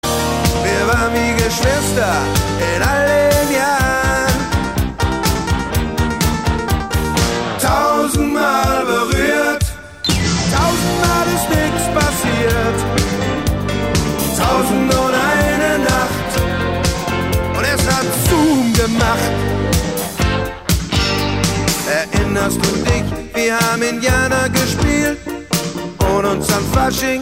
Gattung: Moderner Einzeltitel
Besetzung: Blasorchester
Tonart: B-Dur